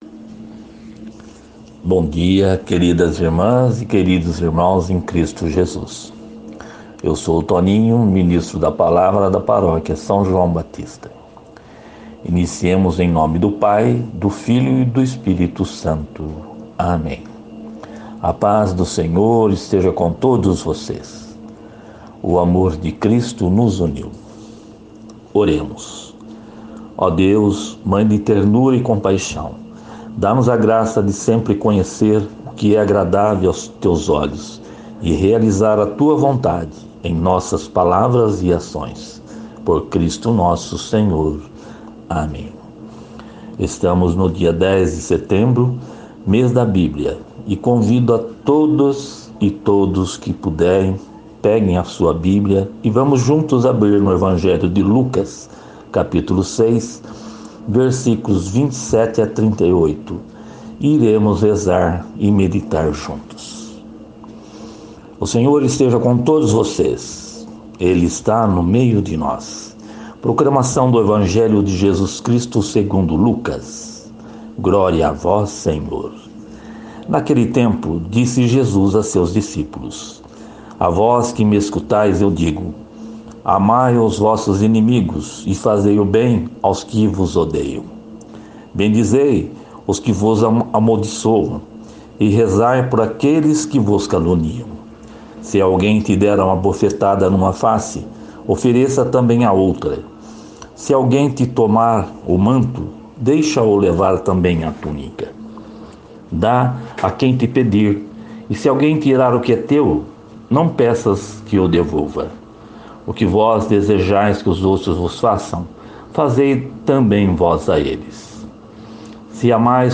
Evangelho do Dia